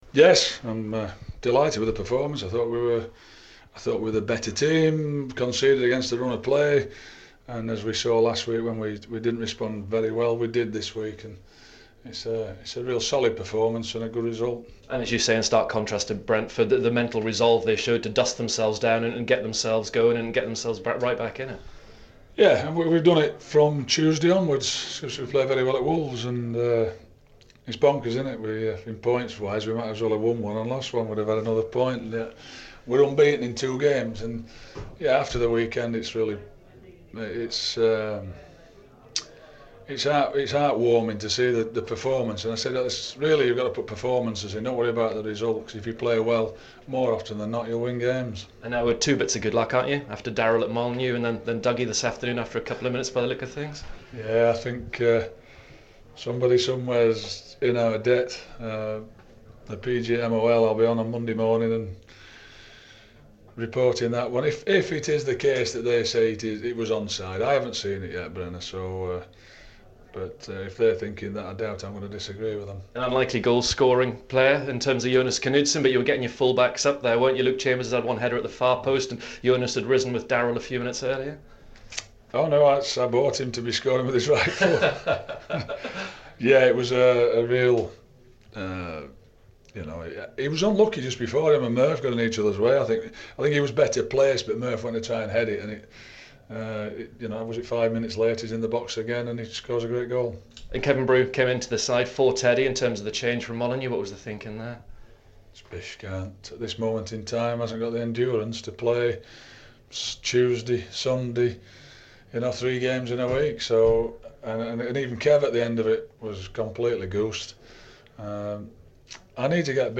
Ipswich Town boss Mick McCarthy speaks after the first East Anglian derby of the season ends in a 1-1 draw.